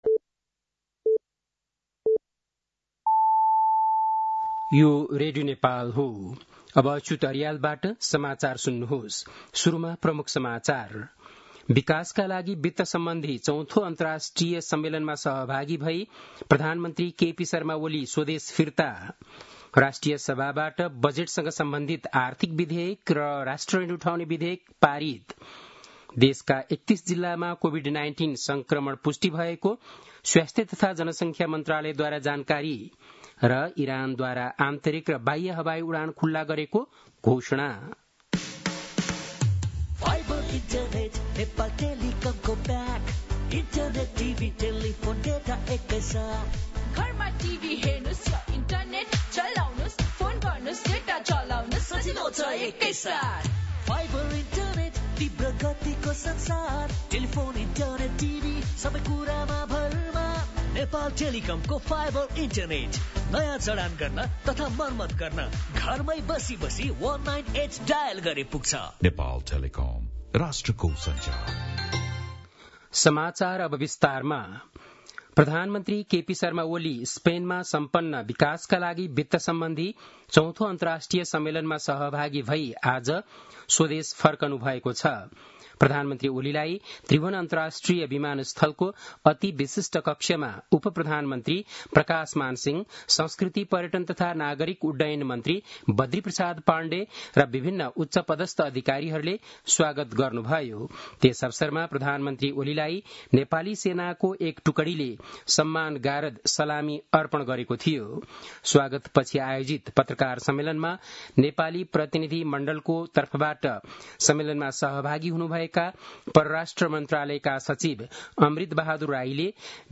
बेलुकी ७ बजेको नेपाली समाचार : २० असार , २०८२